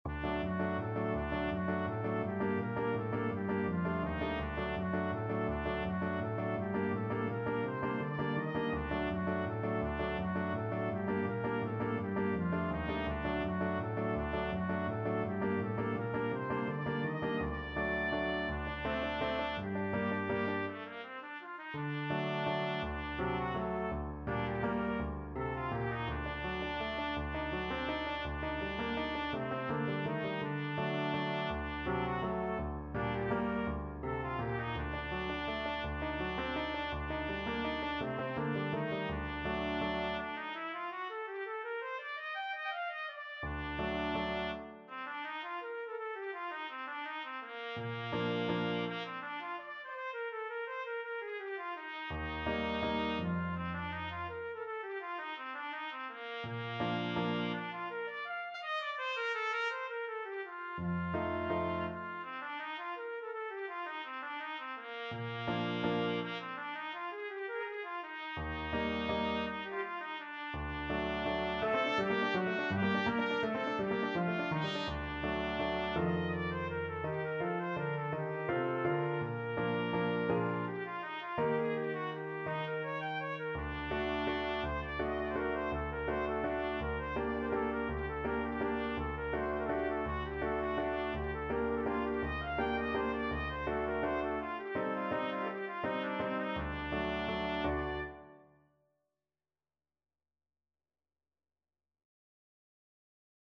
3/4 (View more 3/4 Music)
Classical (View more Classical Trumpet Music)